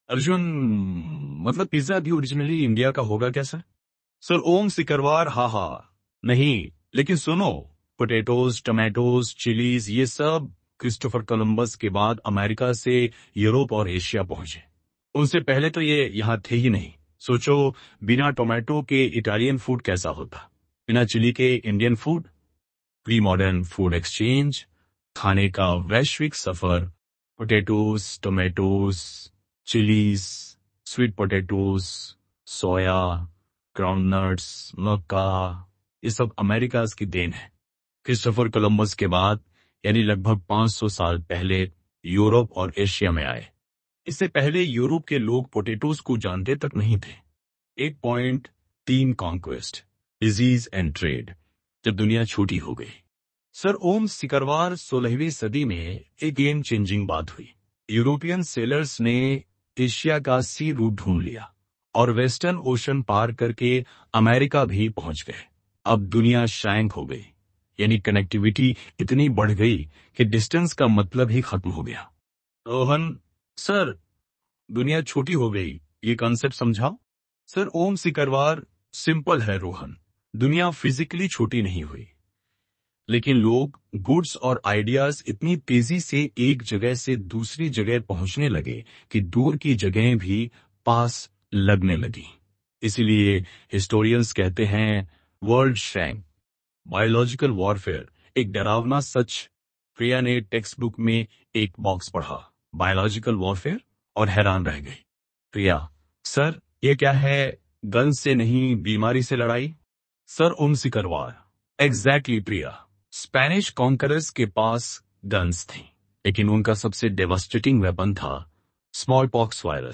kabir_tts_audio-29-1.mp3